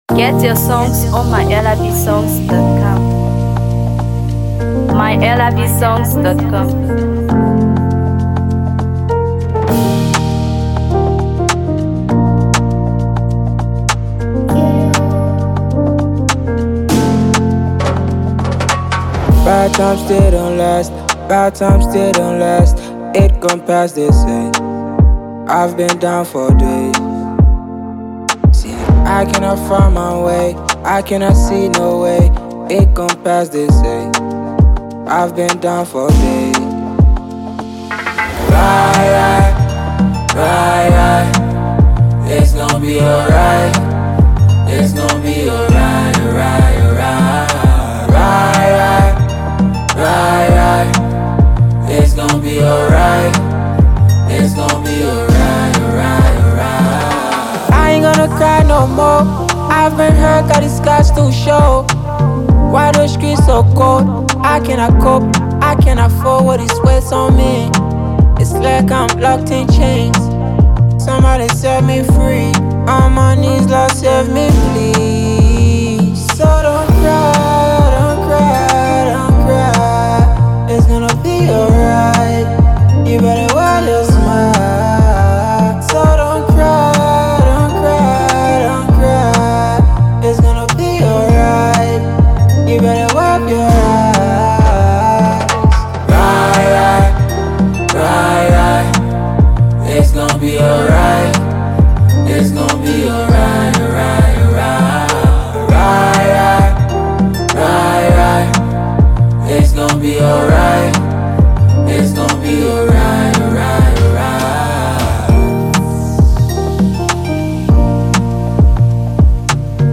Afro PopMusic
With its infectious rhythm and relatable storytelling